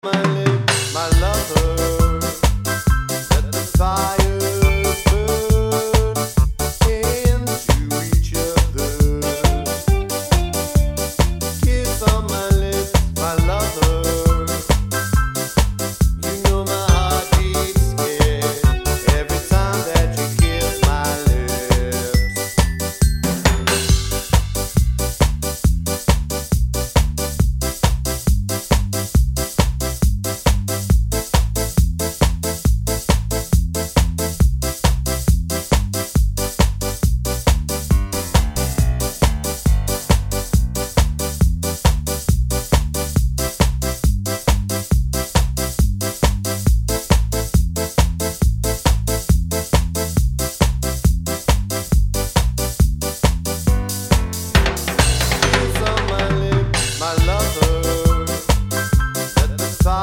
With Cut Down Intro Ska 3:47 Buy £1.50